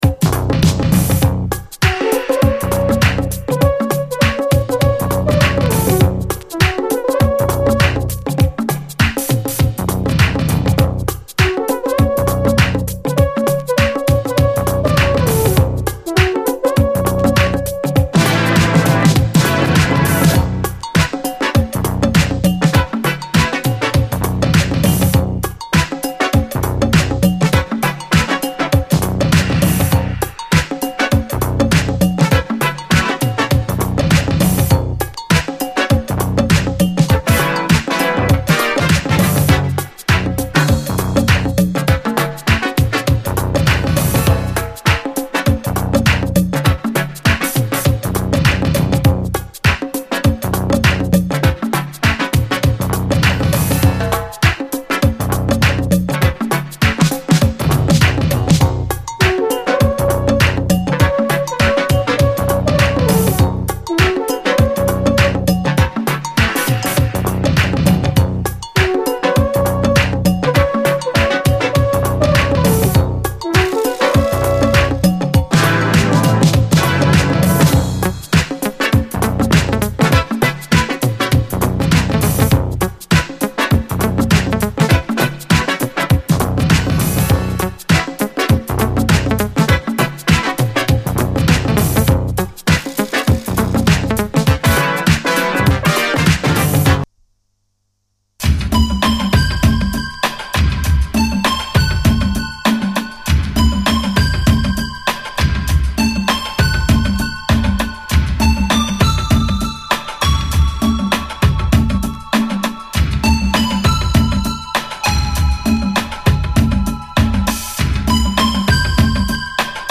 DISCO
幻想的かつトロピカルなシンセ・ワークが主張する、先鋭ダビー・メロウ・ディスコ群！